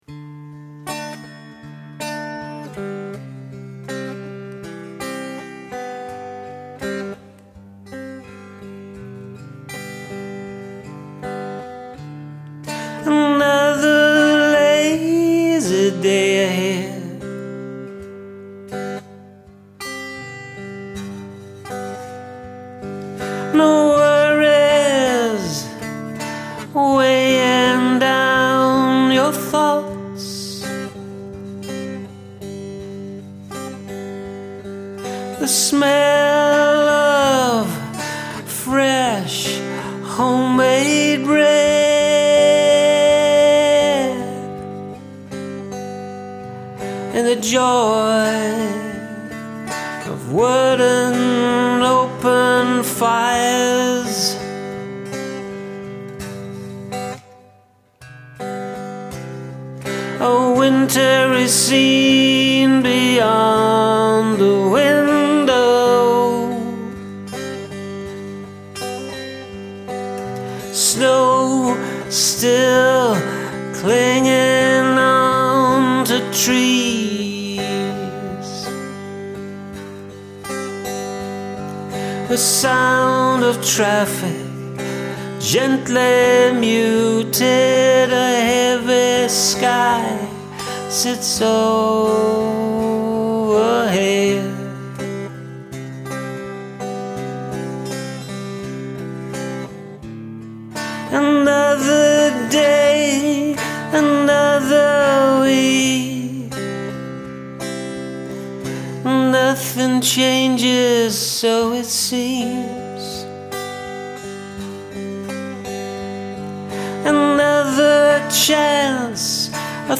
Ok, so this one seems sweet but really it's on the dark side.
Your vocal and melody are wonderful here.
great intense lyrics and yes dark side, but that almost bob dylan style coming through, great vox!